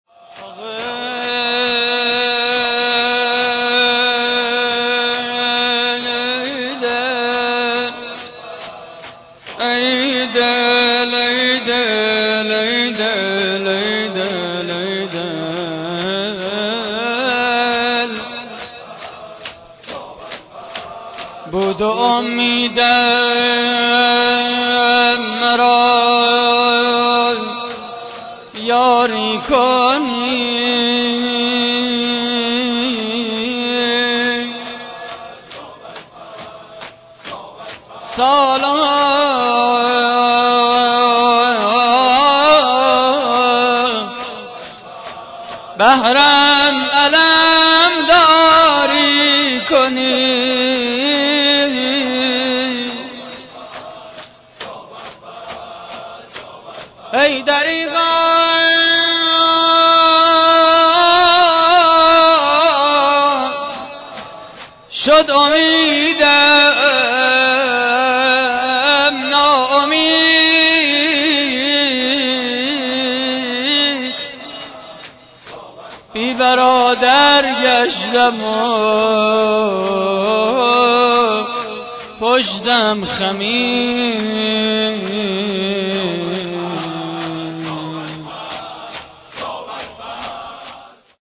مداحی شب 23 رمضان (شهادت حضرت امیر ع) / هیئت کریم آل طاها (ع) - 1 شهریور 90
صوت مراسم:
نغمه خوانی: بود امیدم مرا یاری کنی؛ پخش آنلاین |